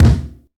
Reverb Steel Kick Drum D Key 239.wav
Royality free kick drum one shot tuned to the D note. Loudest frequency: 342Hz
reverb-steel-kick-drum-d-key-239-Eu2.ogg